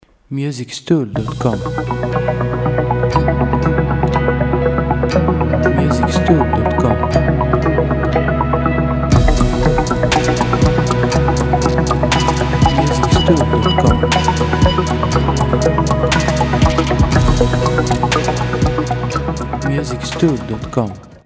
• Type : Instrumental
• Lyrics : No
• Bpm : Moderato
• Genre : New Age / Techno